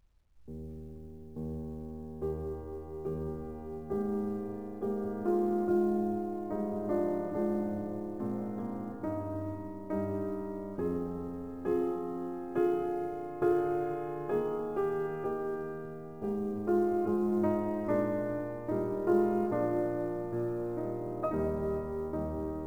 To examine the performance of the non-linear compression, I will use a real (piano music) example to showcase the effects of linear/non-linear dynamic compression.
However, if the signal is compressed non-linearly via the µ-law algorithm to 8 bit as described above (I tried the A-law as well: little difference; I slightly preferred the µ-law):
This sounds much better, despite some remaining whispering in the background.
bach_kurtag_8bit.wav